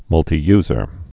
(mŭltē-yzər, -tī-)